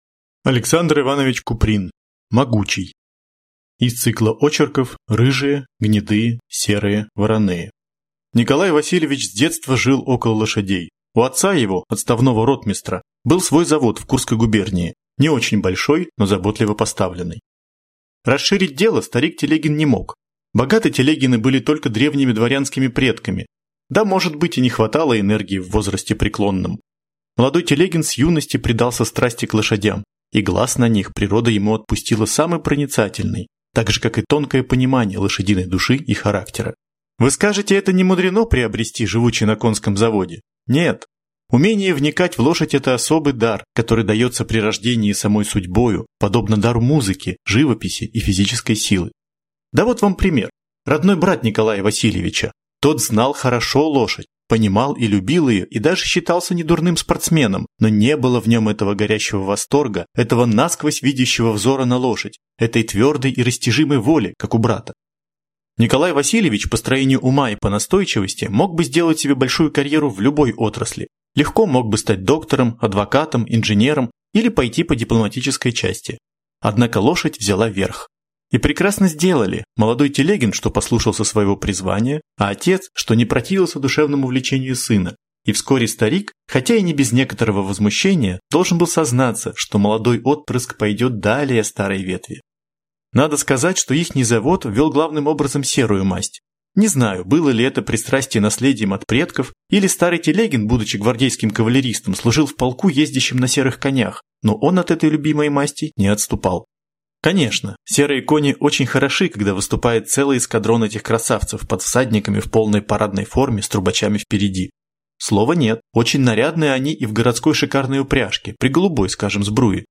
Аудиокнига Могучий | Библиотека аудиокниг